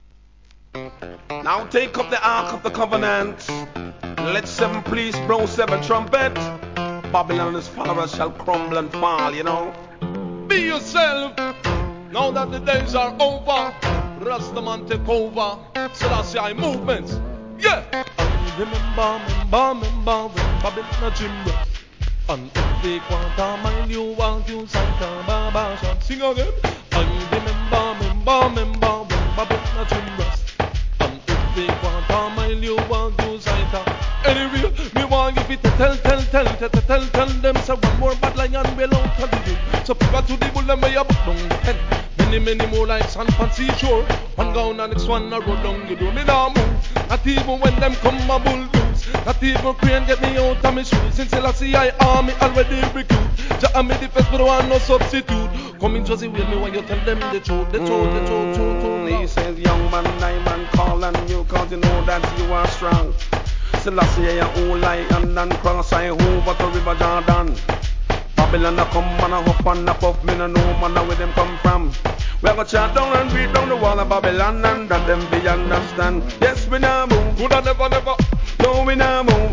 REGGAE
癖になるRHYTHMと歌い回しでBIG UP RASTA !!